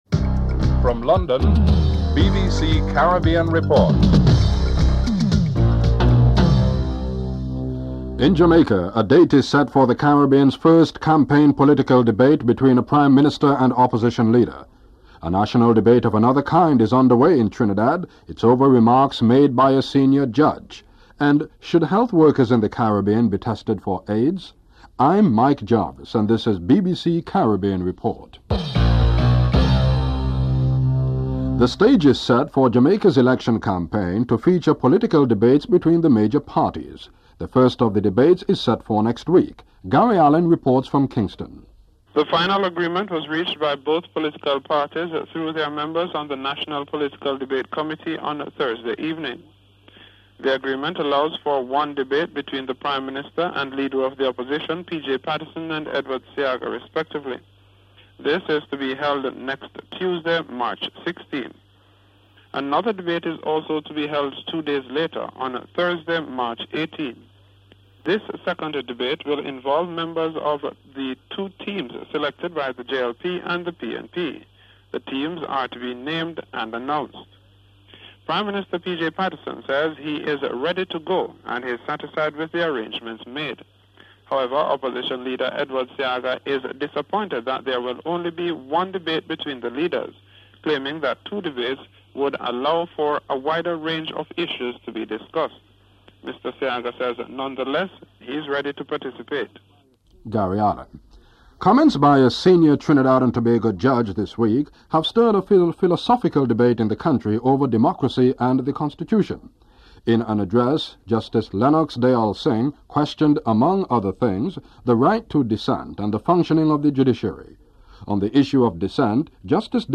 The British Broadcasting Corporation
Headlines with anchor